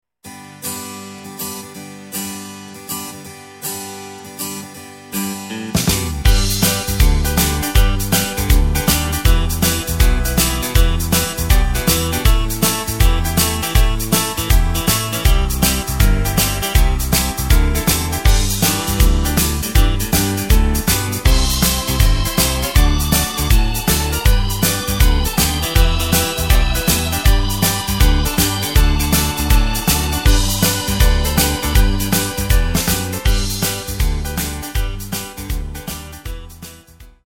Takt:          4/4
Tempo:         159.00
Tonart:            G
Playback mp3 Demo